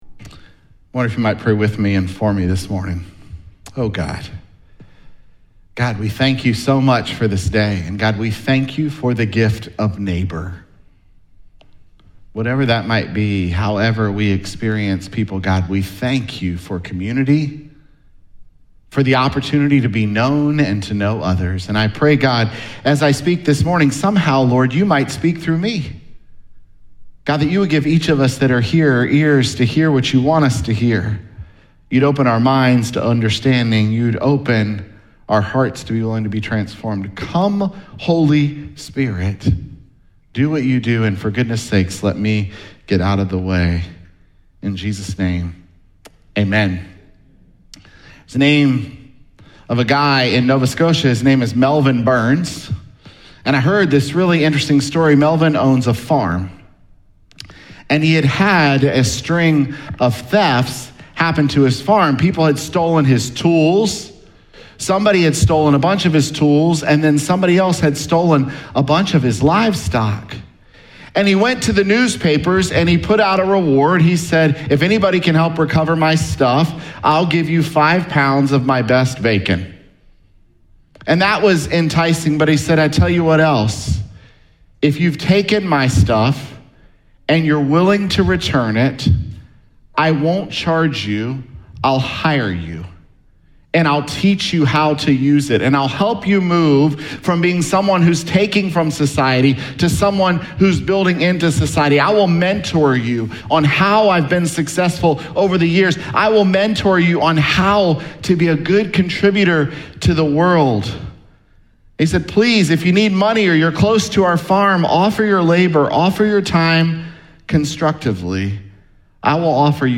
Sep7SermonPodcast.mp3